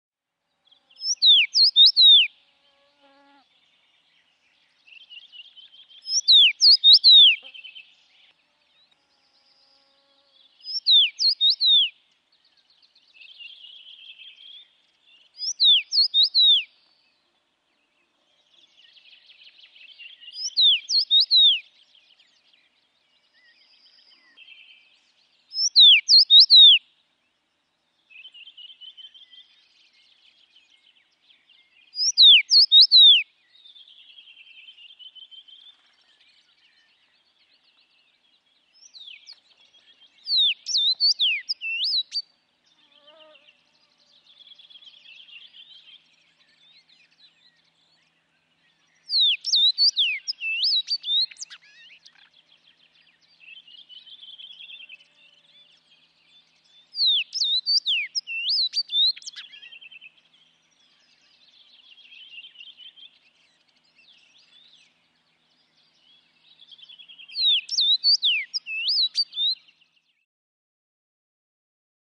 102. PUNAVARPUNEN (rosenfink)
Äänet: Laulu pehmeä, kolme–neljäosainen vihellyssarja (”nice to meet you”).
punavarpunen-copyright-birdlife.mp3